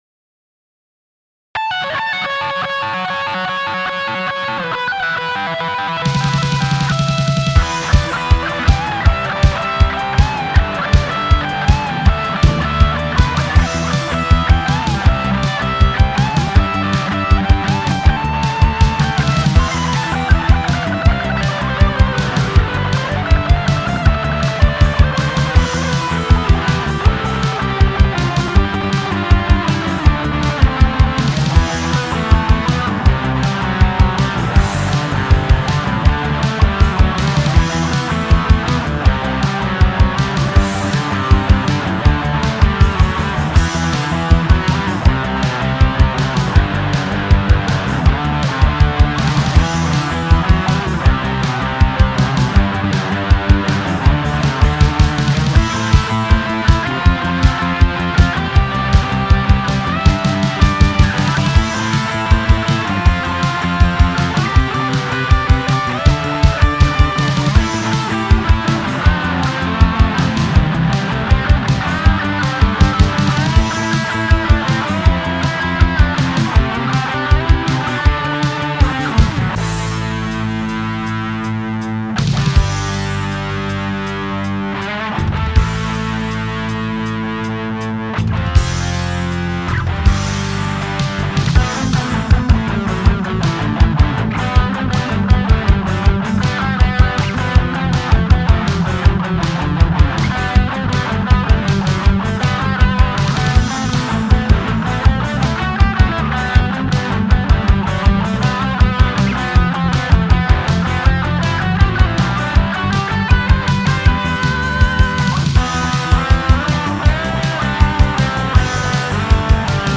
A guitarist
Some of these instrumental songs are not too bad